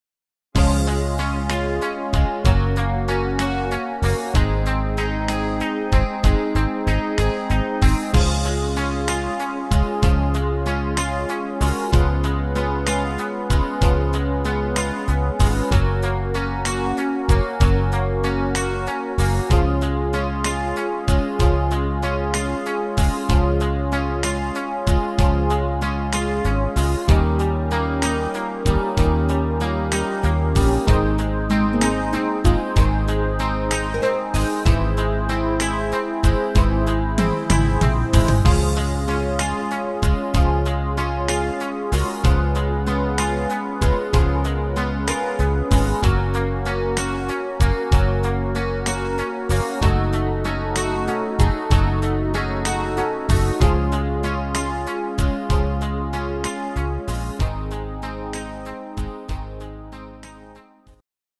Rhythmus  Slow Beat
Art  Schlager 90er, Deutsch, Weibliche Interpreten